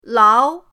lao2.mp3